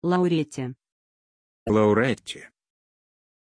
Pronunciación de Laurette
pronunciation-laurette-ru.mp3